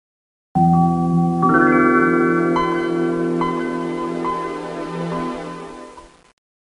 toggle-sound.mp3